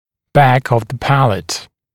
[bæk əv ðə ‘pælət][бэк ов зэ ‘пэлэт]задняя часть нёба